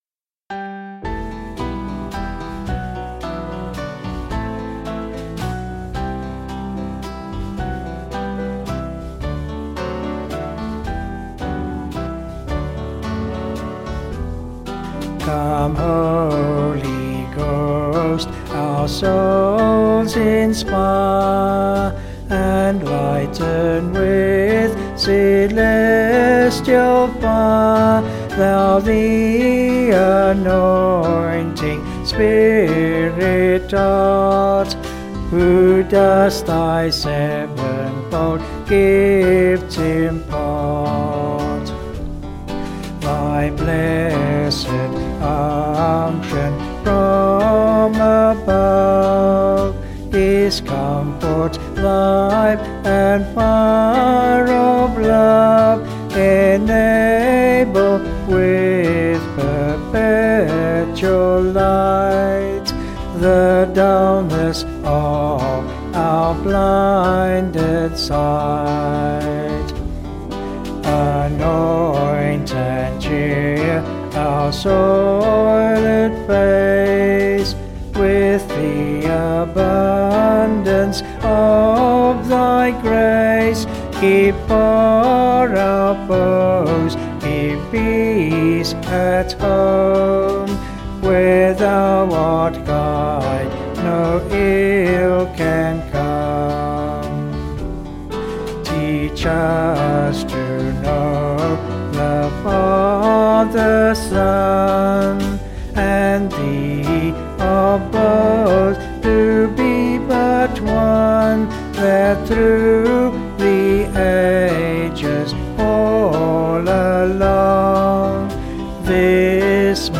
Vocals and Band   263kb Sung Lyrics